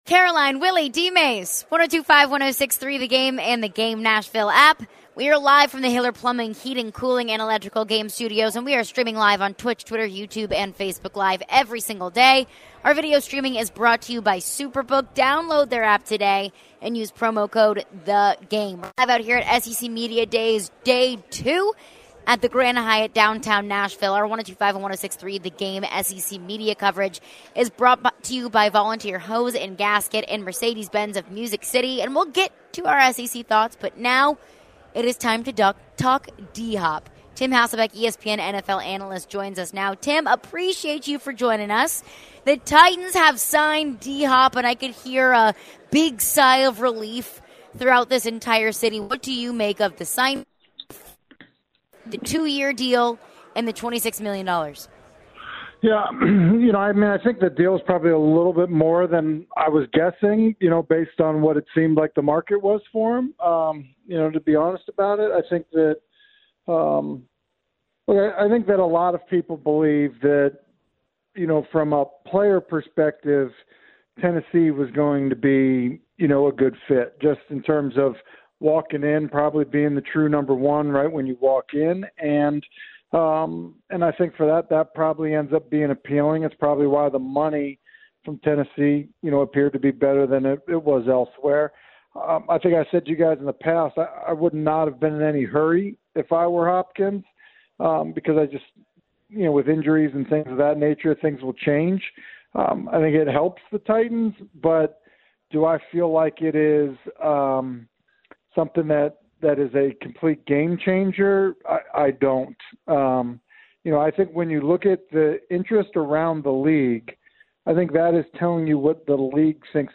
Tim Hasselbeck Interview (7-18-23)